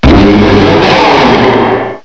sovereignx/sound/direct_sound_samples/cries/abomasnow_mega.aif at 4adc2dcb16dbe1a6fd2e5684e3a0ae64ba6763c7